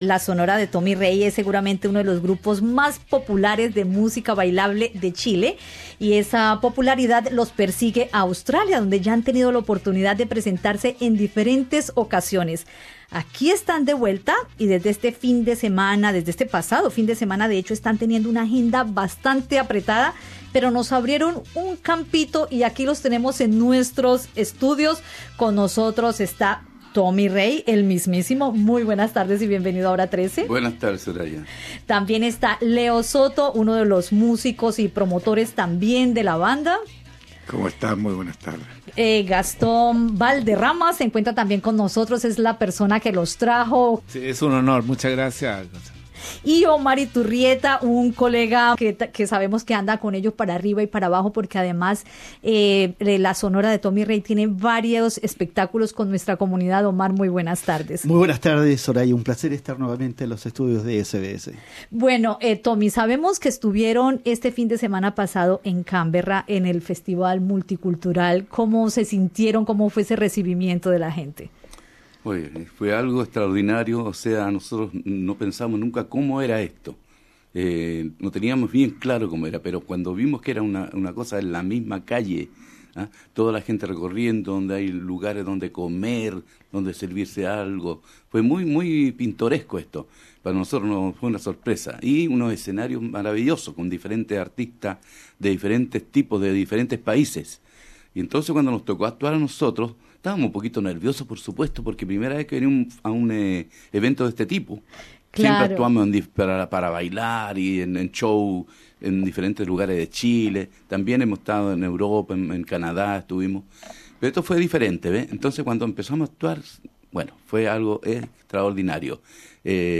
Rescatamos esta entrevista de 2016 en recuerdo de la visita de la banda chilena a Australia y en memoria de Tommy Rey, quien falleció el 26 de marzo de 2025.
La sonora de Tommy Rey en los estudios de Radio SBS Source: SBS